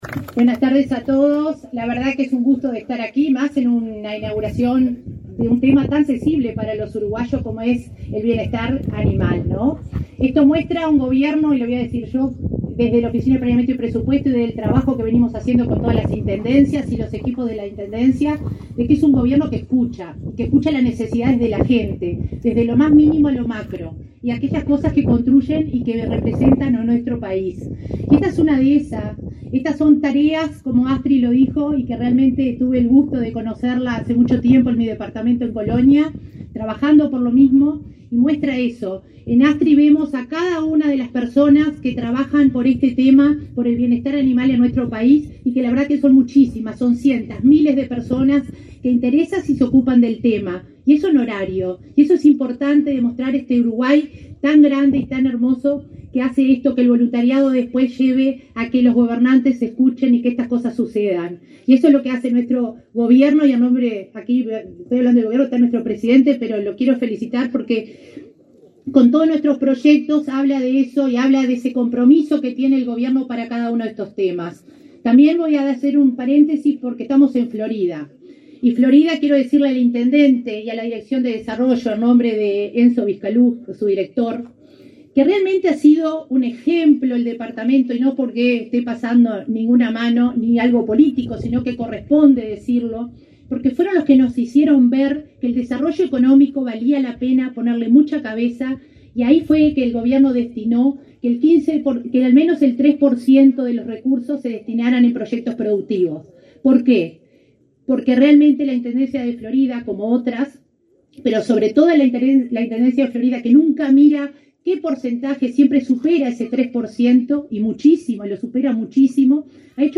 Palabras de la coordinadora de la OPP, María de Lima
Con la presencia del presidente de la República, Luis Lacalle Pou, fue inaugurado, este 27 de setiembre un refugio transitorio para animales callejeros, que ocupa unas 11 hectáreas, en Florida. En la oportunidad, la coordinadora general de Descentralización y Cohesión de la Oficina de Planeamiento y Presupuesto (OPP), María de Lima, destacó el compromiso del Gobierno respecto al bienestar animal.